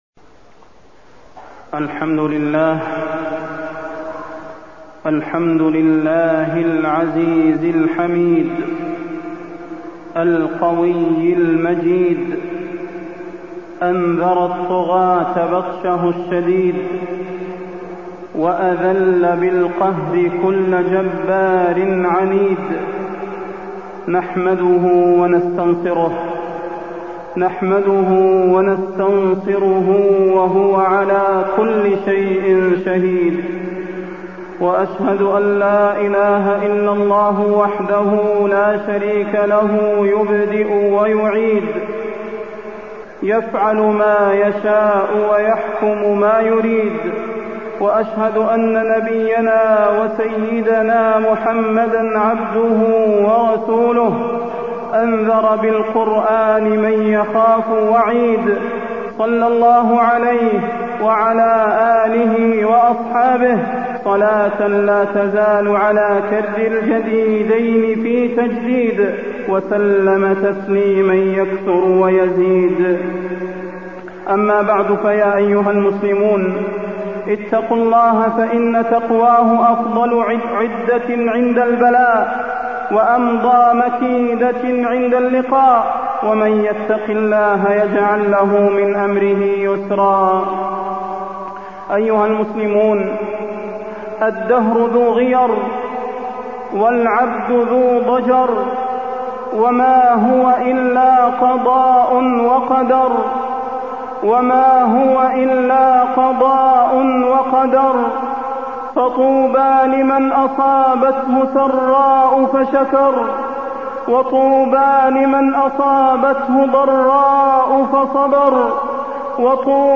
فضيلة الشيخ د. صلاح بن محمد البدير
تاريخ النشر ٢٠ شوال ١٤٢٥ هـ المكان: المسجد النبوي الشيخ: فضيلة الشيخ د. صلاح بن محمد البدير فضيلة الشيخ د. صلاح بن محمد البدير واقع الأمة الإسلامية